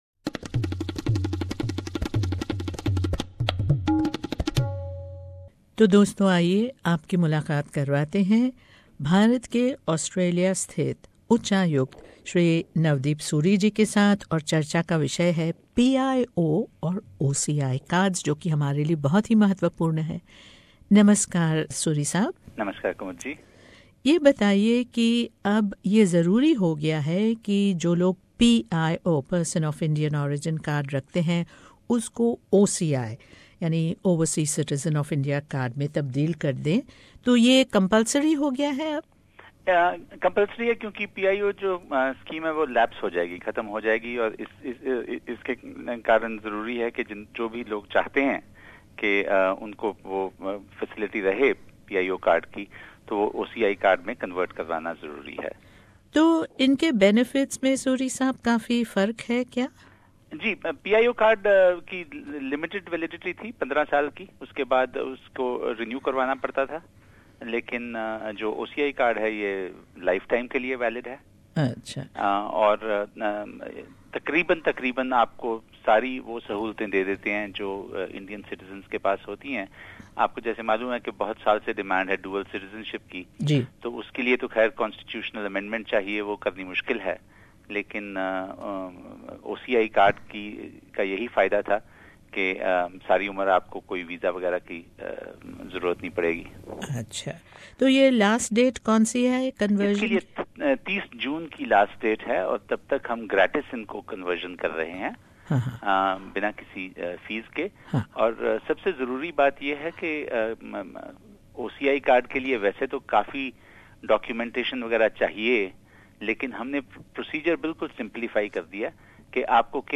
The Indian High Commissioner in Australia Mr Navdeep Suri gives us an update on the conversion of PIO Cards to OCI cards. The process has been simplified to make it user friendly...Mr Suri also gives us a brief glimpse of the imminent Indian Art and Culture Festival called, "Confluence" to be held in Australian cities during September and October this year.